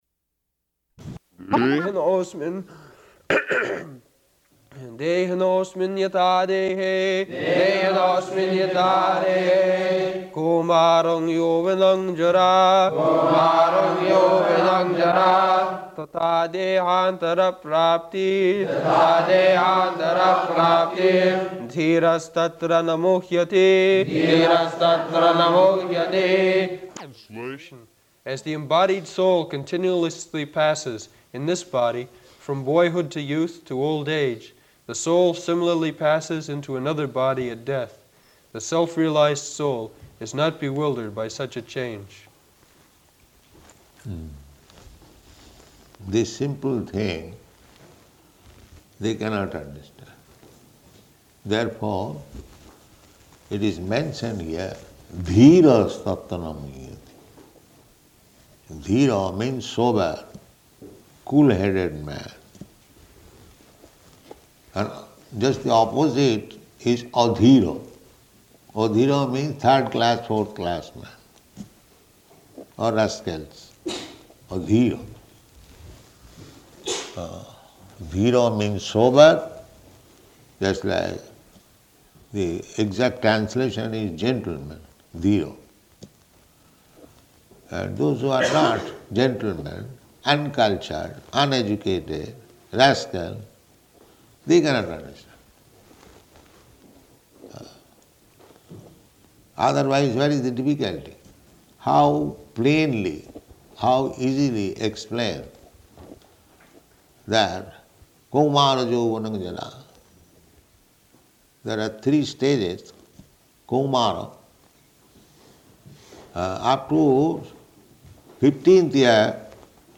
Location: London
[leads chanting of verse] [Prabhupāda and devotees repeat]